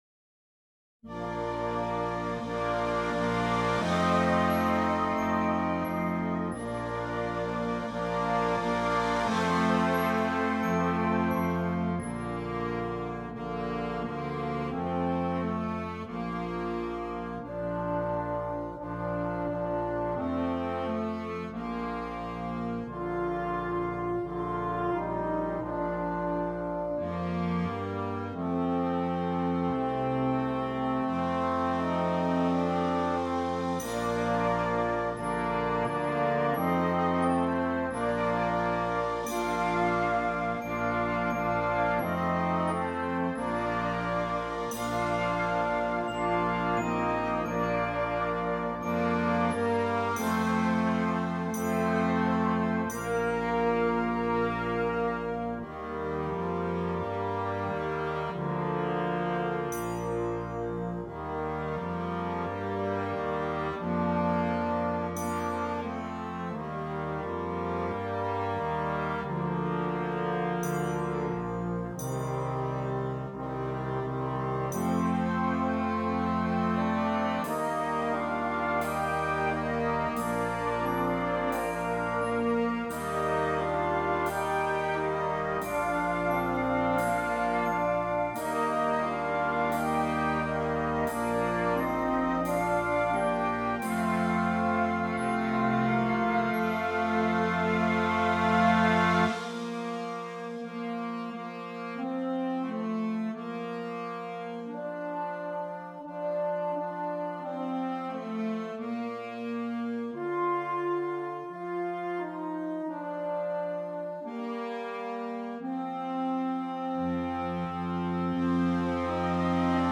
Flexible Band